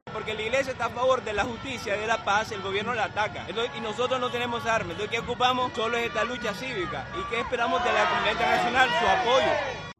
Manifestante Iglesia Nicaragua